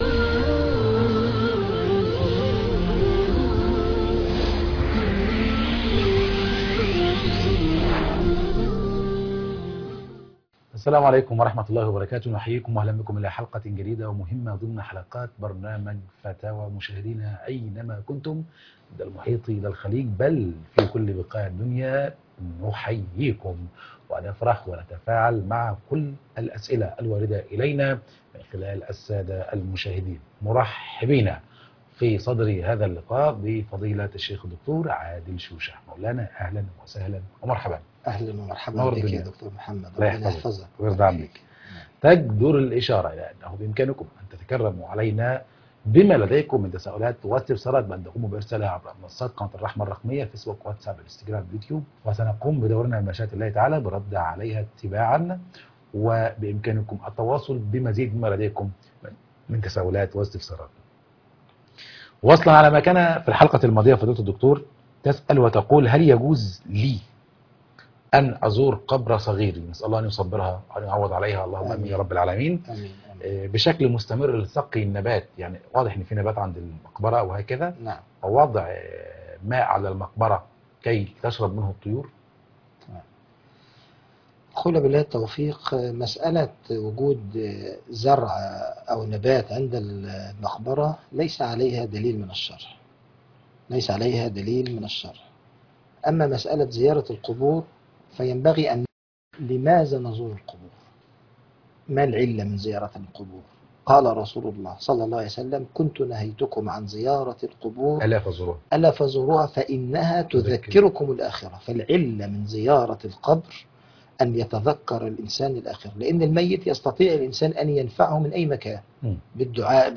فتاوي